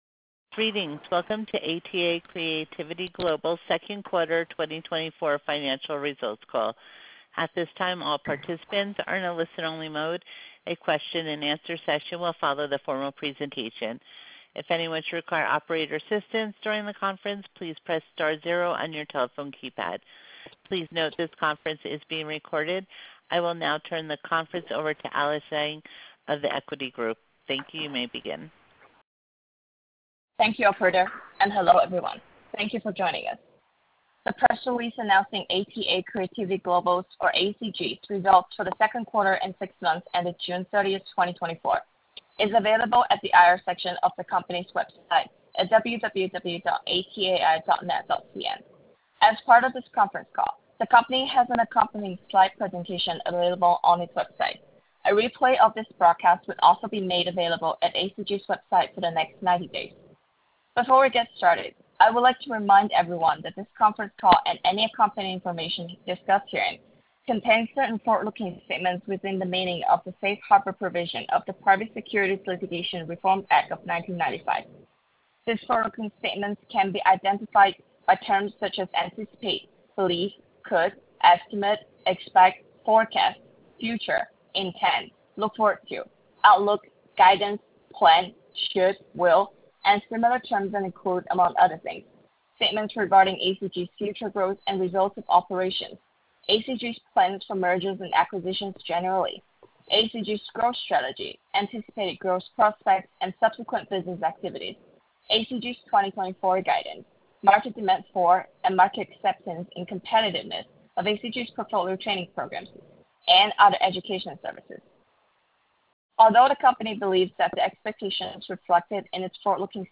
Earnings Webcast Q2 2024 Audio